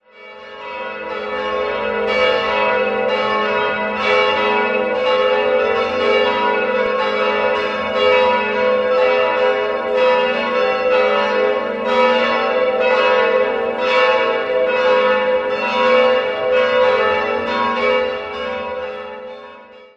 Christusglocke a'-2 500 kg 930 mm 1951 Otto, Bremen-Hemelingen Marienglocke h'+0 350 kg 828 mm 1951 Otto, Bremen-Hemelingen Schutzengelglocke cis''-2 250 kg 735 mm 1951 Otto, Bremen-Hemelingen Totenglocke e''+4 150 kg 618 mm 1951 Otto, Bremen-Hemelingen Quelle